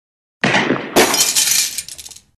Звуки пистолета
Из пистолета стреляют в бутылку